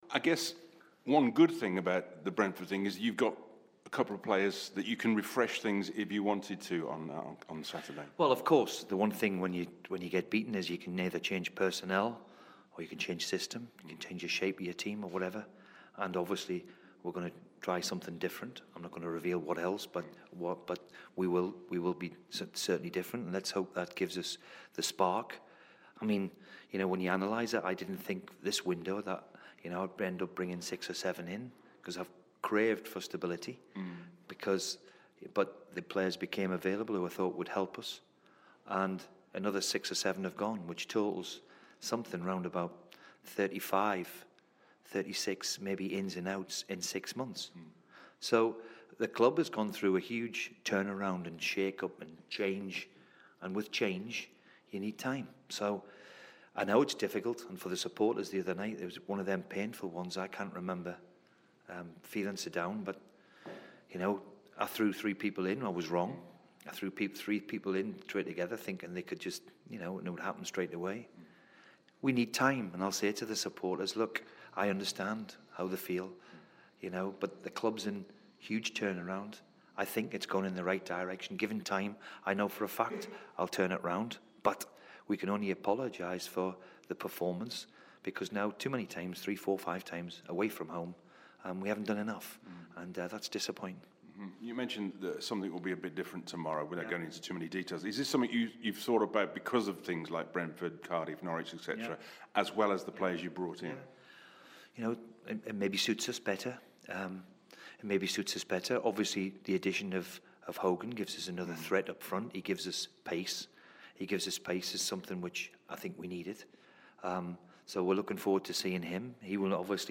Aston Villa boss Steve Bruce speaks to BBC WM ahead of their trip to Nottingham Forest...